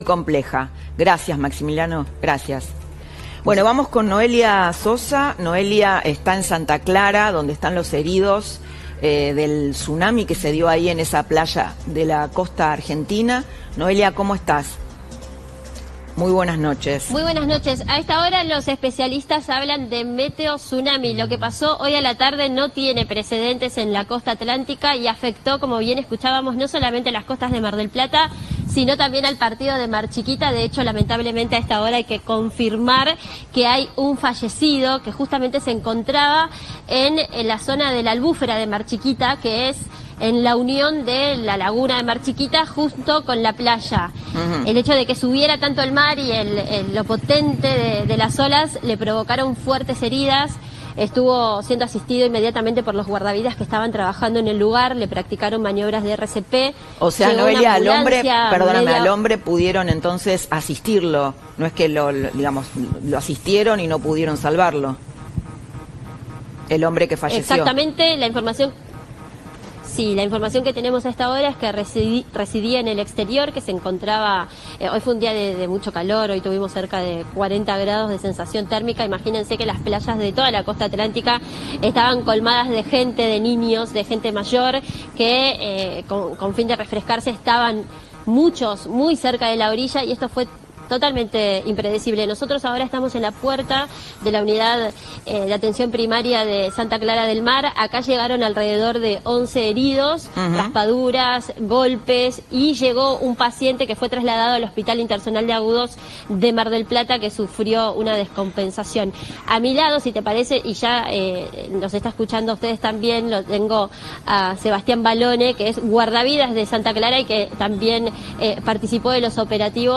«Fue muy rápido»: el relato de los guardavidas tras el meteotsunami que enlutó a la Costa Atlántica.
GUARDAVIDAS-HICIERON-GRAN-TRABAJO.mp3